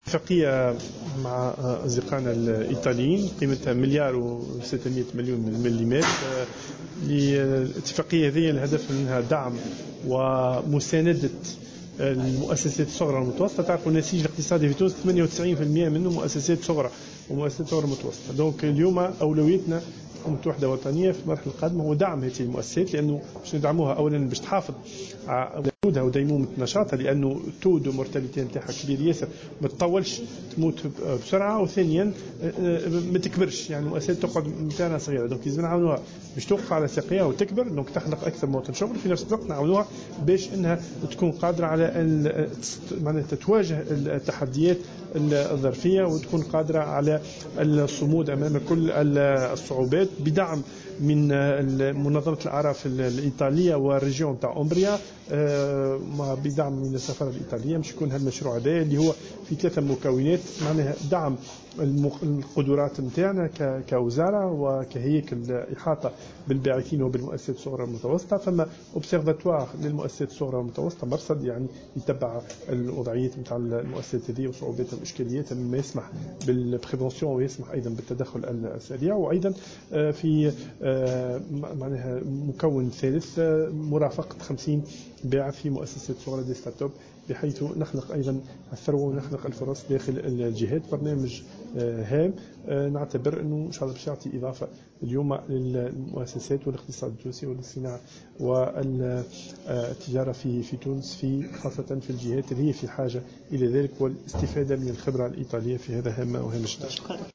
وقال وزير الصناعة والتجارة، زياد العذاري في تصريح إعلامي إن الاتفاقية تهدف إلى دعم ومساندة المؤسسات الصغرى والمتوسطة للحفاظ على ديمومة نشاطها، مشيرا إلى أن مشروع التعاون يتضمن 3 محاور أساسية تهم تطوير القدرات الفنية للهياكل المكلفة بالمؤسسات الصغري والمتوسطة ومحاضن المؤسسات وإحداث مرصد وطني للمؤسسات الصغرى والمتوسطة والإحاطة بـ50 مشروع .يذكر أن هذا البرنامج سينفذ على مدى 3 سنوات بداية من جانفي 2017 بميزانية تبلغ 1.628 مليون دينار.